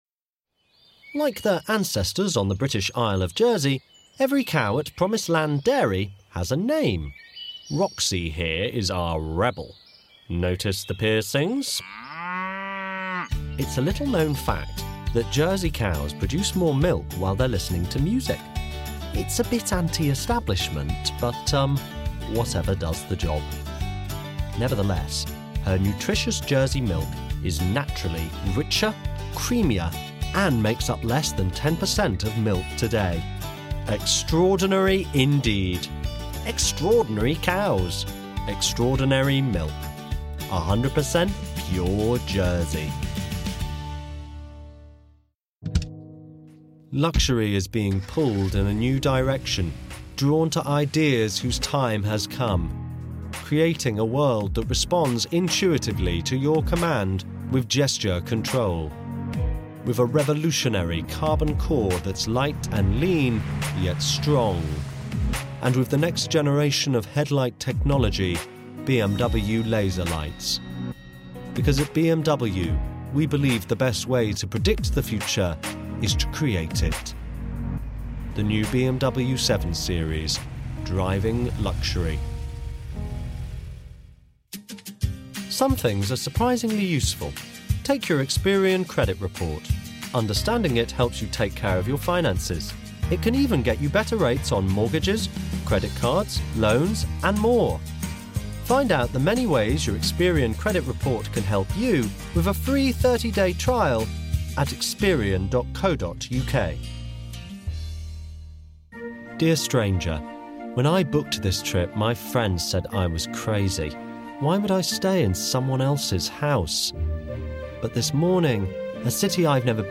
Female
Authoritative , Character , Confident , Corporate , Friendly , Natural , Reassuring , Smooth , Warm , Versatile